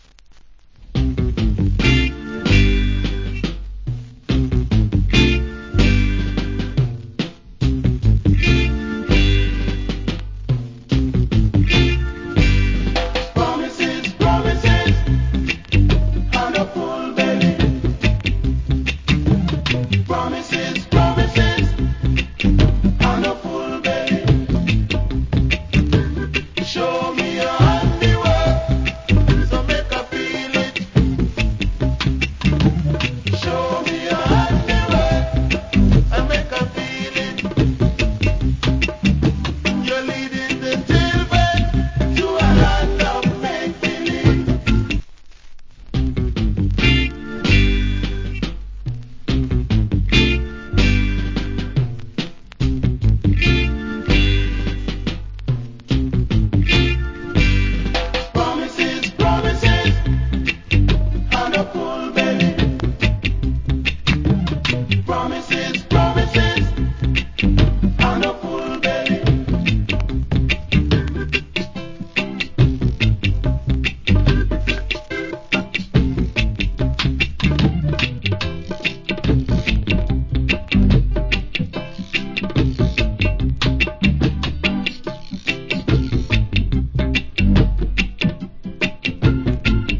Wicked Reggae Vocal.